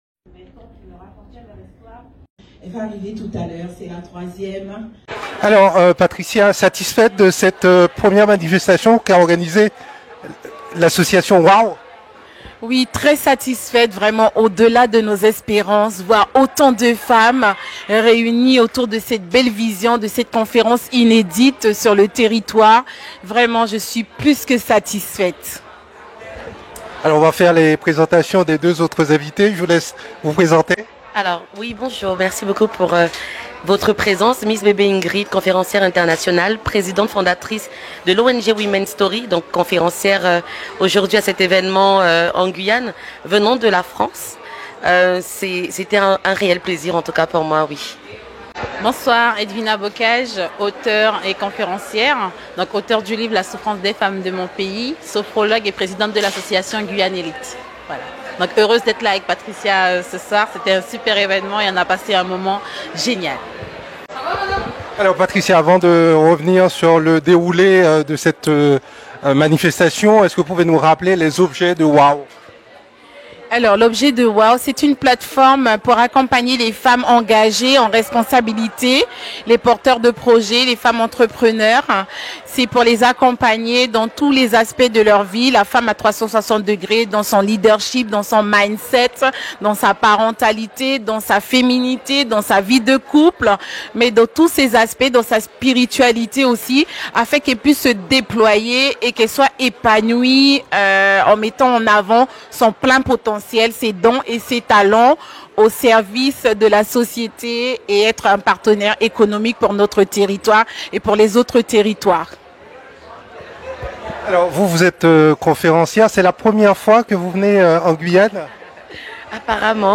Radio Mayouri Campus était sur place, dans le cadre d'une émission spéci